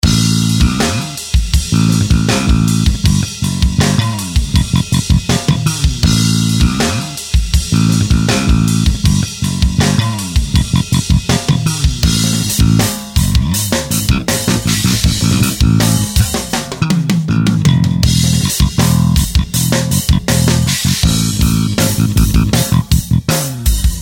VST plugin ��� ������